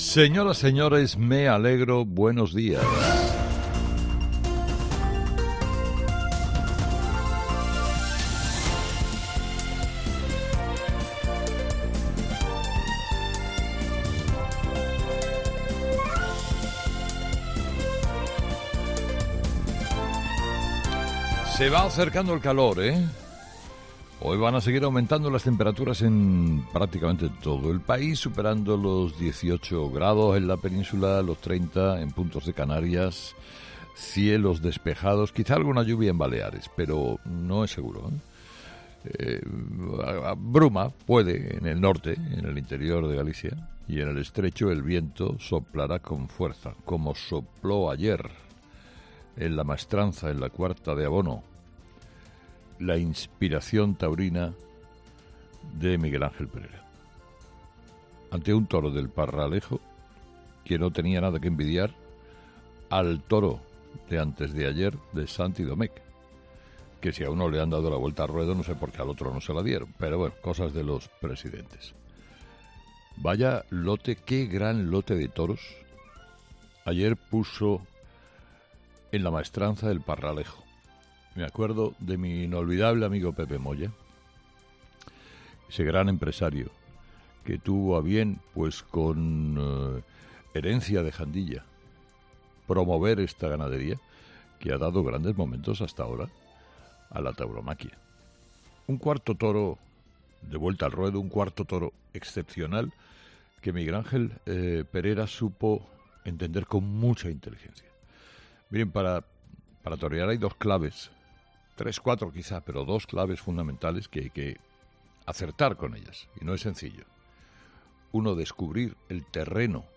Carlos Herrera, director y presentador de 'Herrera en COPE', comienza el programa de este jueves analizando las principales claves de la jornada que pasan, entre otras cosas, por Pedro Sánchez y la situación de la vivienda en España.